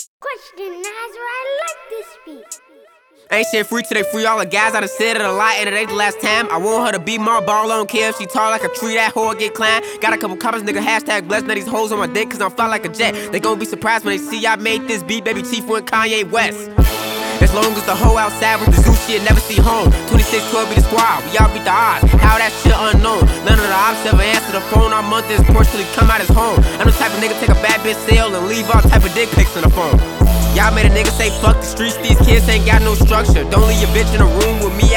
Жанр: Хип-Хоп / Рэп